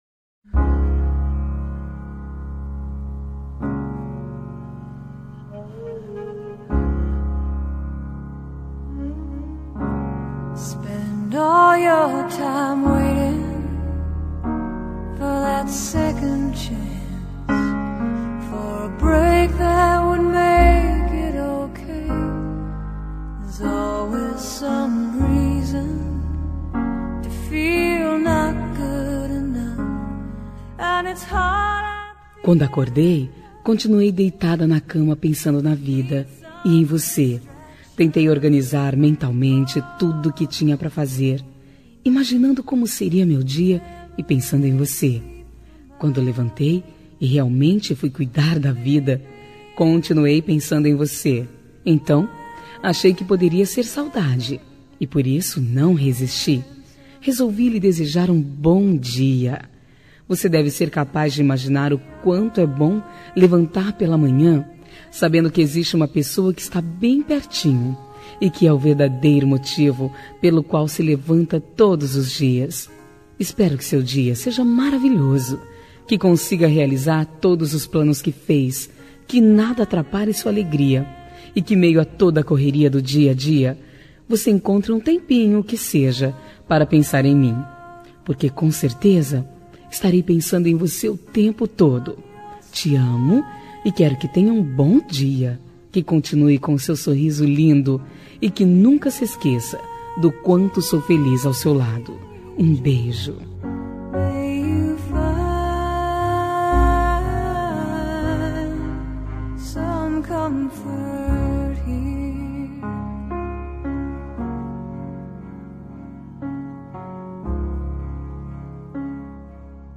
Telemensagem de Bom dia – Voz Feminina – Cód: 6304 – Romântica
6304-b-dia-fem-roamntico.mp3